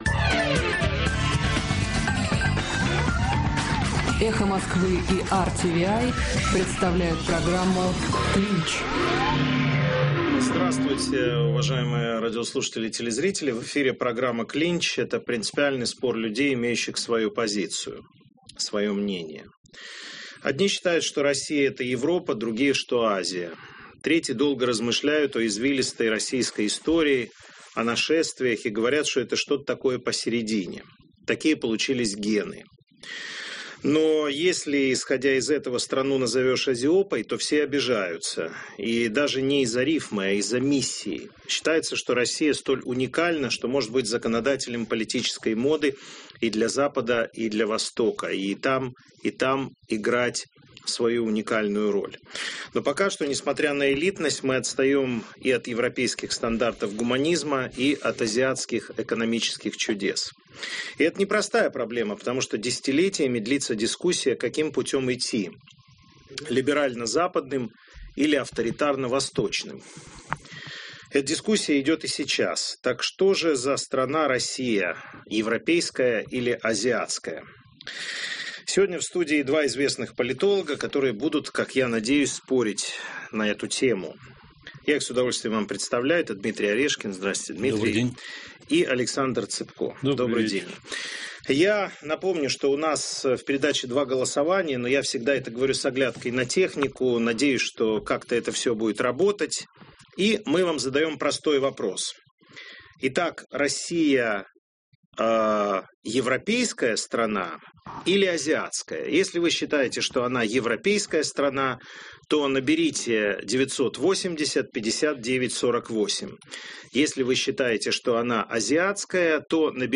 Это принципиальный спор людей, имеющих свою позицию, свое мнение.
Так что же за страна Россия, европейская или азиатская. Сегодня в студии два известных политолога, которые будут, как я надеюсь, спорить на эту тему.